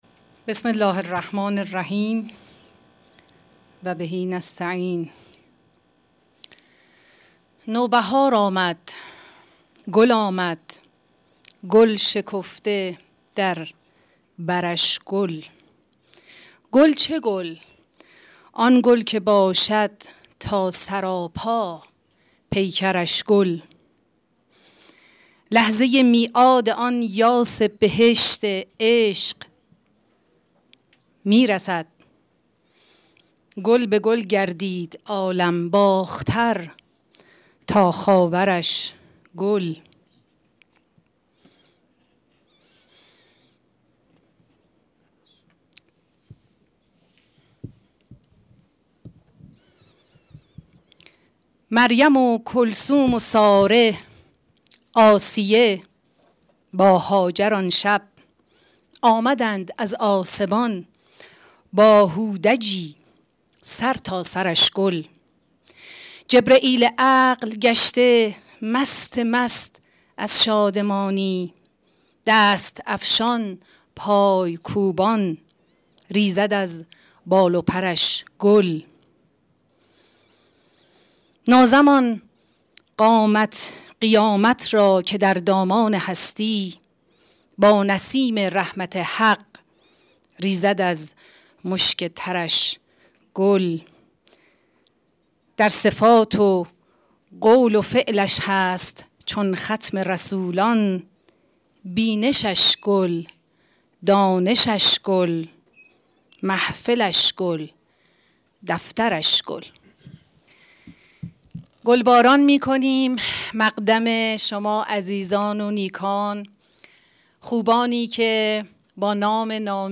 نشست تخصصی گفتگو محور بازخوانی حیات اجتماعی اخلاقی بانو فاطمه زهرا س